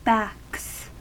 Ääntäminen
Ääntäminen US Tuntematon aksentti: IPA : /bæks/ Haettu sana löytyi näillä lähdekielillä: englanti Backs on sanan back monikko.